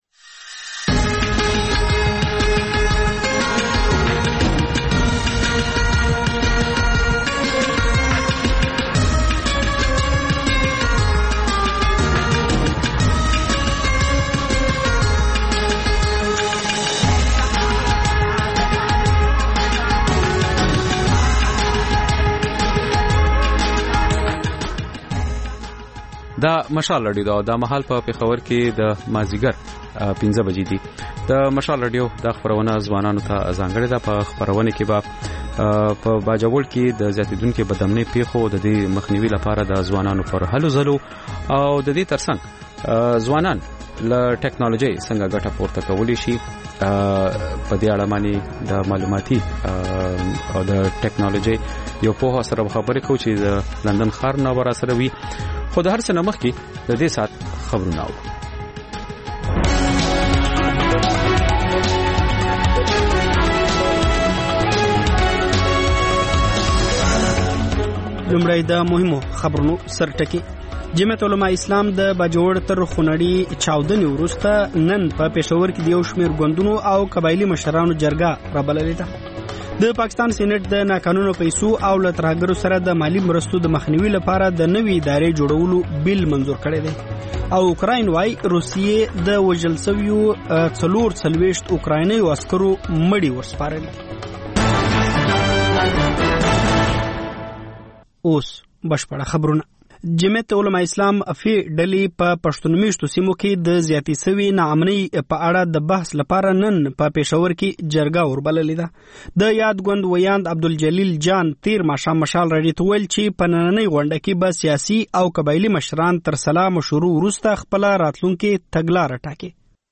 د خپرونې پیل له خبرونو کېږي، بیا ورپسې رپورټونه خپرېږي.
ځېنې ورځې دا ماښامنۍ خپرونه مو یوې ژوندۍ اوونیزې خپرونې ته ځانګړې کړې وي چې تر خبرونو سمدستي وروسته خپرېږي.